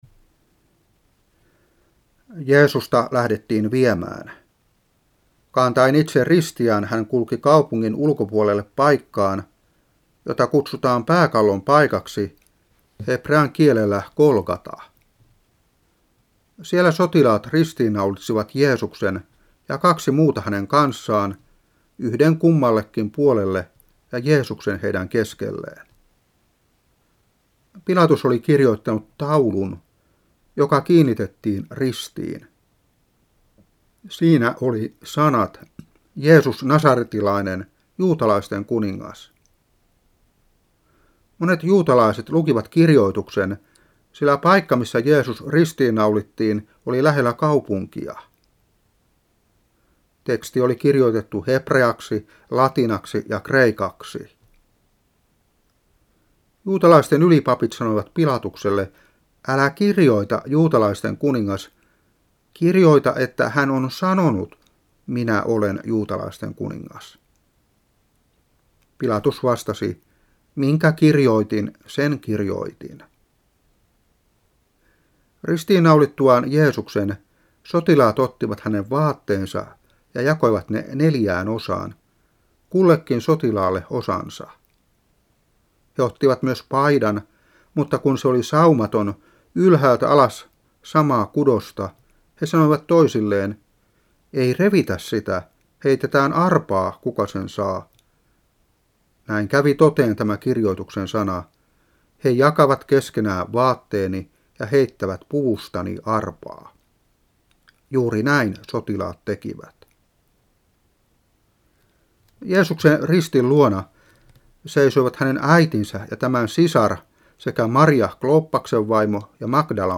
Saarna 2000-4. 1.Moos.22:1-13.